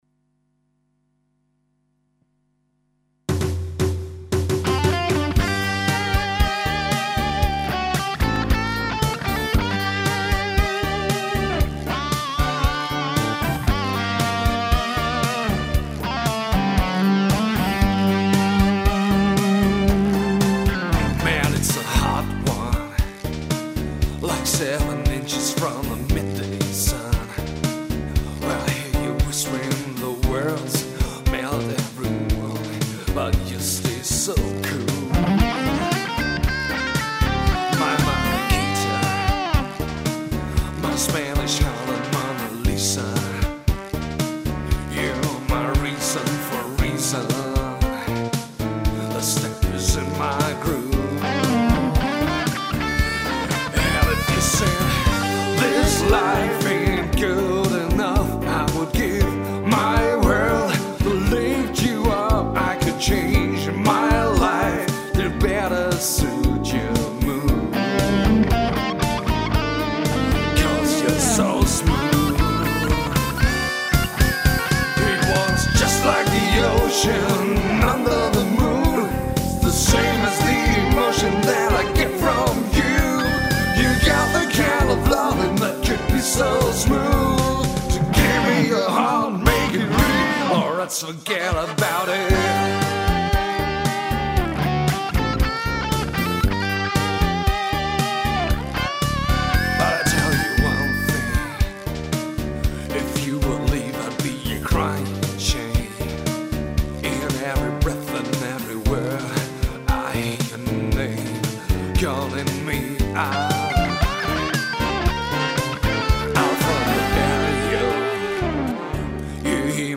• Duo/Trio/Quartett
• Coverband